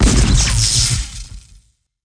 SFX高频电流攻击技能音效下载
SFX音效